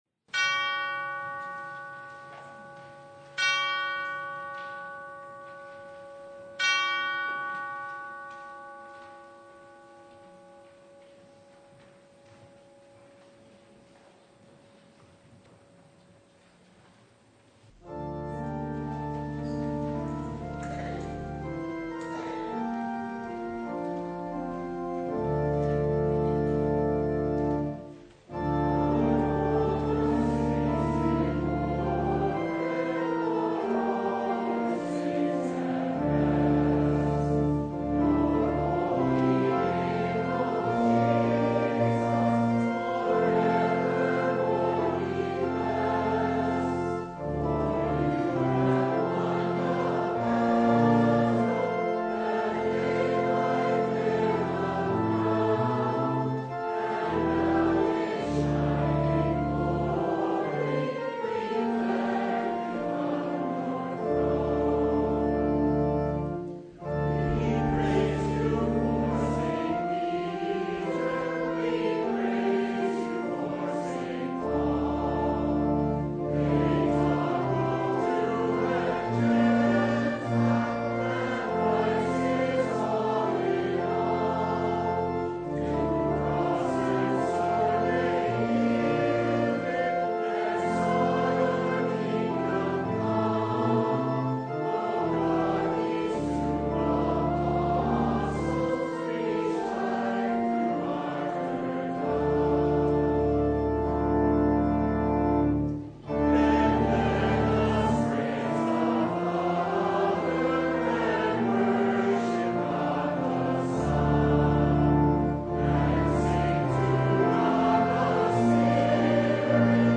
Preacher: Visiting Pastor